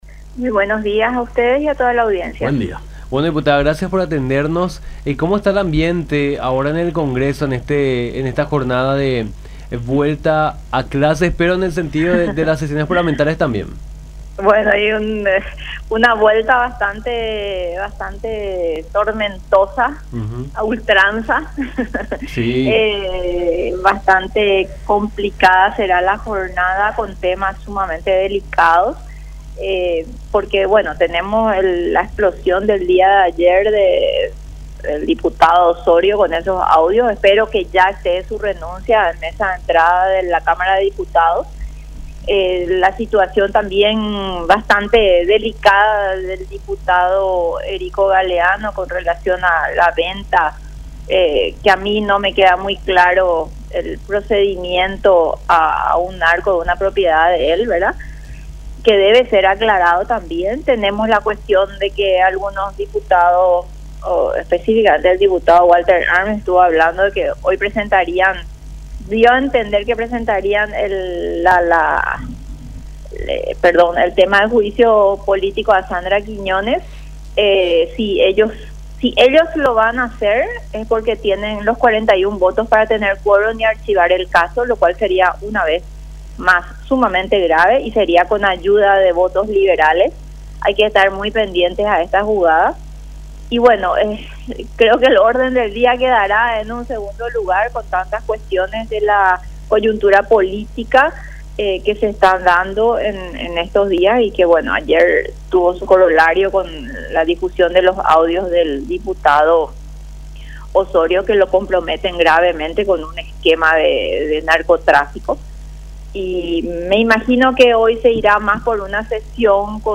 “Es una vuelta a sesiones bastante tormentosas, ‘A Ultranza Py’ realmente. No se qué jugada podría presentar el colega Ozorio, pero espero que ya esté su renuncia presentada en mesa de entrada en Cámara de Diputados”, dijo Rocío Vallejo, diputada del Partido Patria Querida, en diálogo con Nuestra Mañana por La Unión